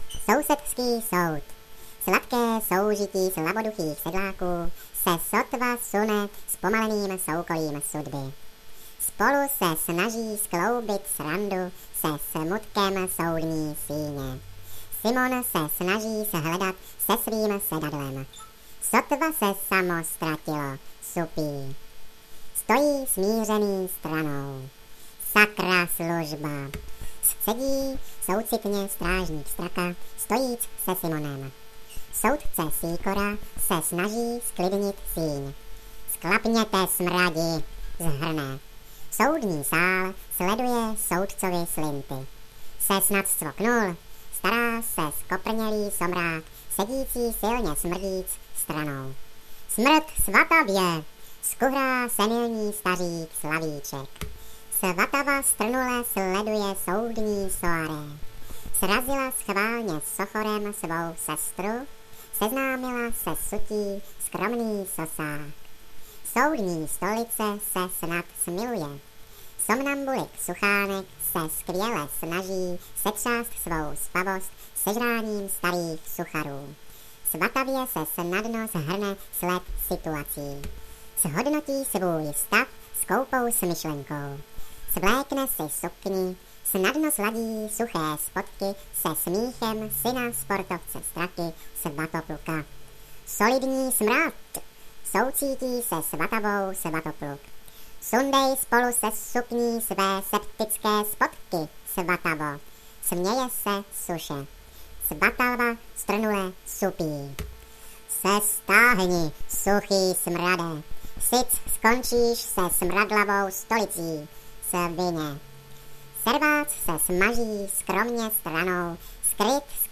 Hezký šmoulovský hlas,je to srandovní
Nádherný hlas.....nádhera...úchvatné...zase budu mít vrásky, co m ito děláš , už nejsem nejmladší ...ale tomuto se nejde smát ...ty máš čas na takové blbinky ?